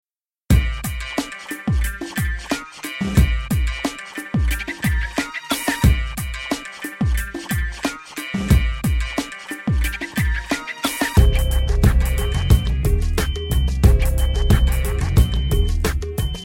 French Raps Song Lyrics and Sound Clip